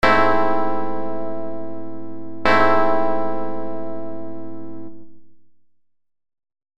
We might write that down as Am/G#, and ‘at face value’ it will sound like this: